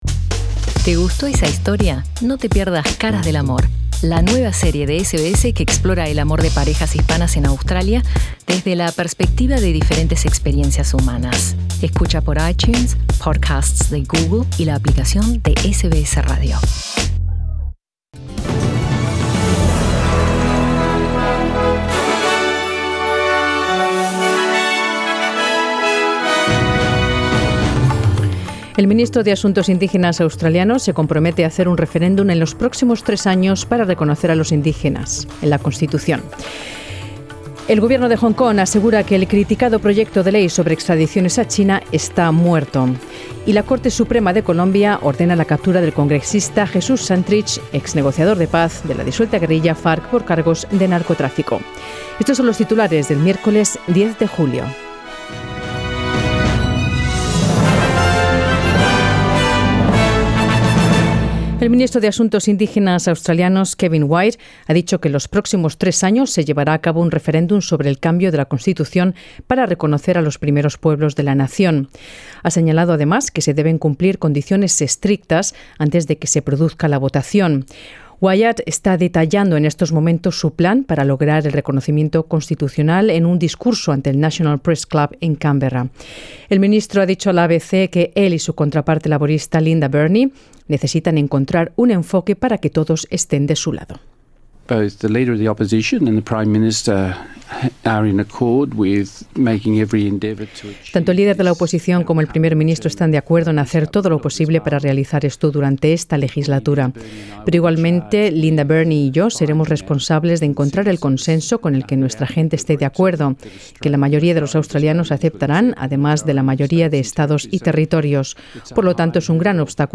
Noticias SBS Spanish | 10 julio 2019